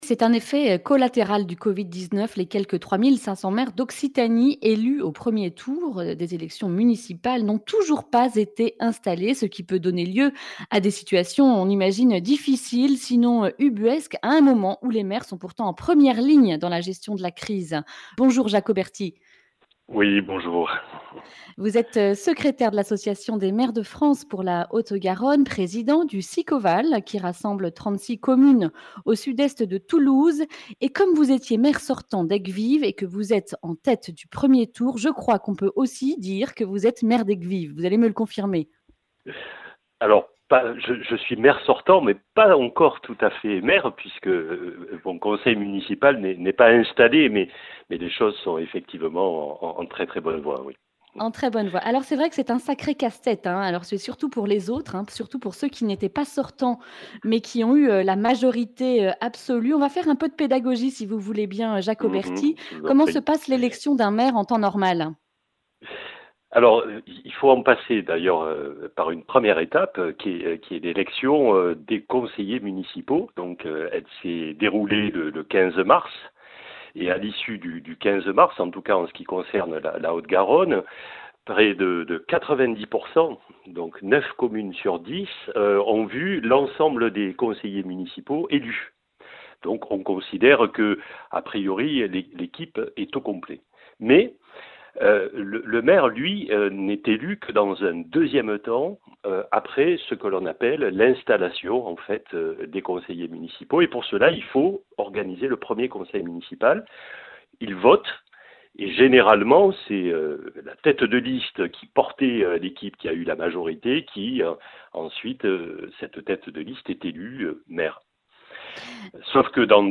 Confinement oblige, les quelques 3500 maires d’Occitanie arrivés en tête du premier tour des élections municipales du 15 mars 2020, n’ont toujours pas pris leurs fonctions. Ce qui donne lieu parfois à des situations ubuesques, comme nous le confie Jacques Oberti, maire sortant d’Ayguesvives, président du SICOVAL et secrétaire de l’Association des Maires de France en Haute-Garonne.
Accueil \ Emissions \ Information \ Régionale \ Le grand entretien \ Covid-19 : ces maires pas encore installés...mais en première ligne !